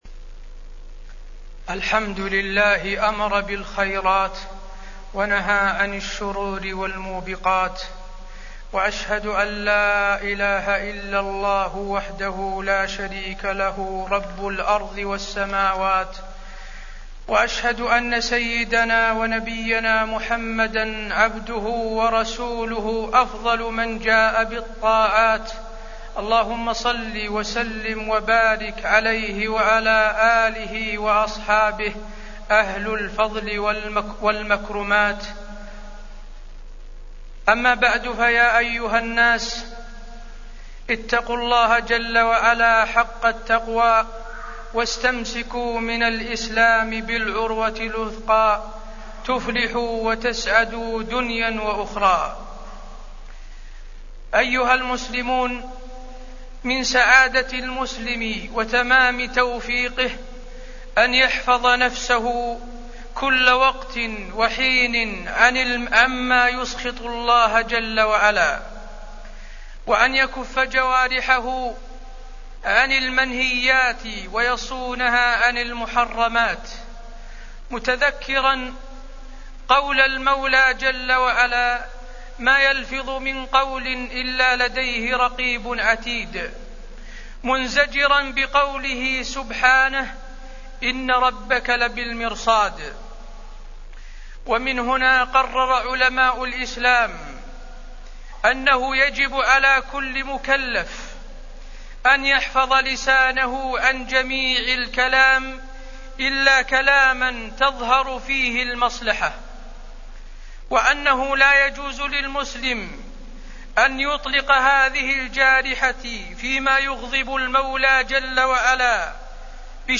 تاريخ النشر ٢٩ صفر ١٤٢٩ هـ المكان: المسجد النبوي الشيخ: فضيلة الشيخ د. حسين بن عبدالعزيز آل الشيخ فضيلة الشيخ د. حسين بن عبدالعزيز آل الشيخ حفظ اللسان